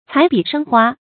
彩筆生花 注音： ㄘㄞˇ ㄅㄧˇ ㄕㄥ ㄏㄨㄚ 讀音讀法： 意思解釋： 生花：長出花來。比喻才思有很大的進步 出處典故： 元 湯式《賞花時 戲賀友人新娶》：「翠袖分香行處有， 彩筆生花 夢境熟。」